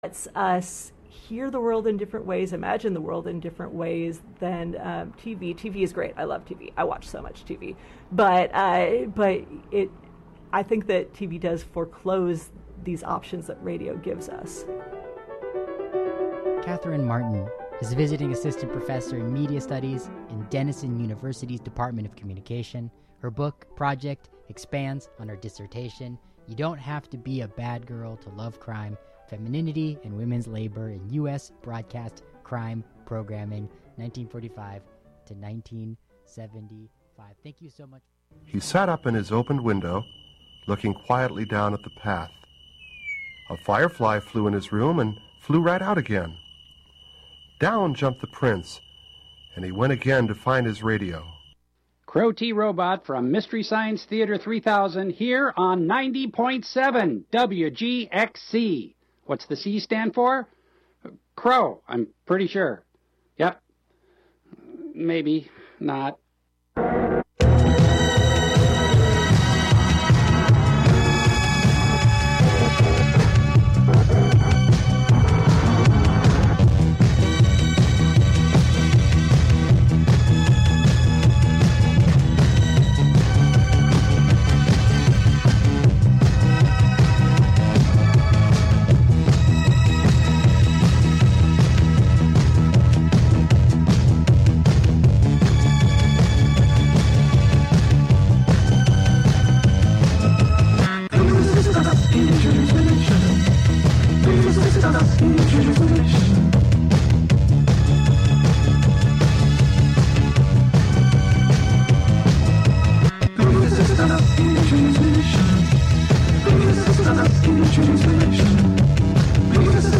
Contributions from many WGXC programmers.